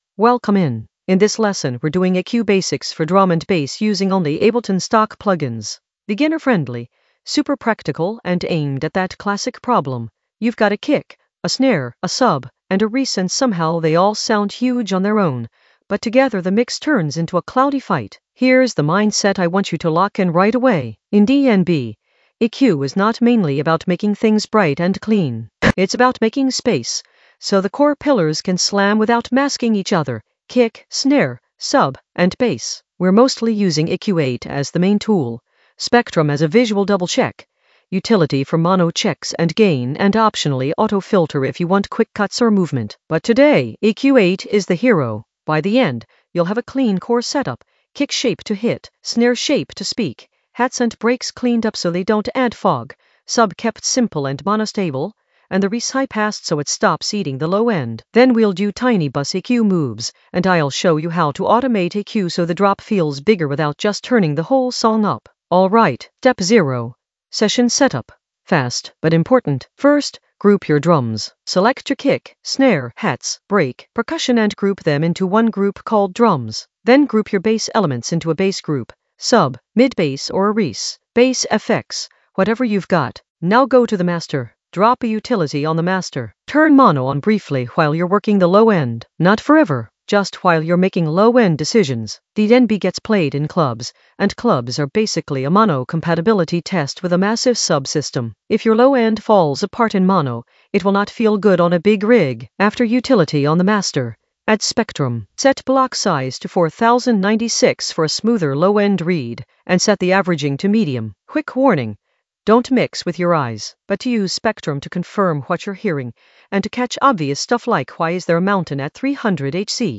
An AI-generated beginner Ableton lesson focused on EQ basics for DnB with stock plugins in the Mixing area of drum and bass production.
Narrated lesson audio
The voice track includes the tutorial plus extra teacher commentary.